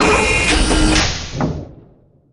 New Door Sounds